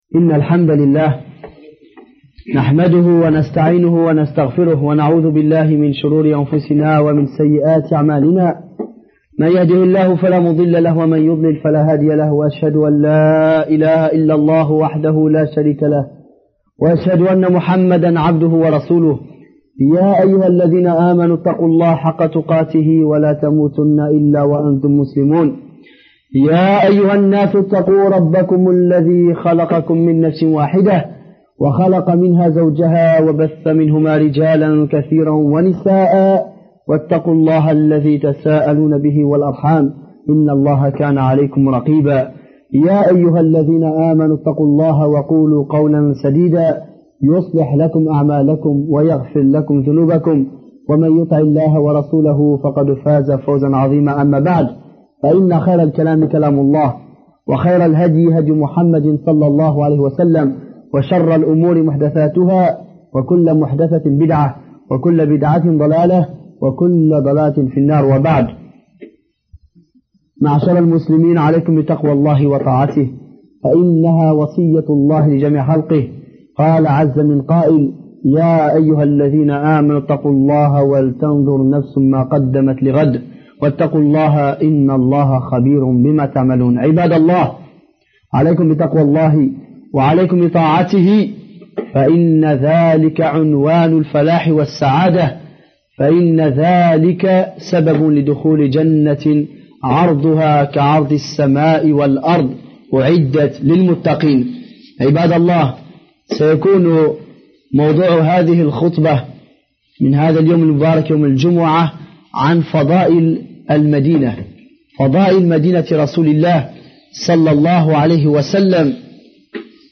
Voici un cours